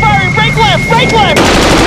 Guns_Break_Left.ogg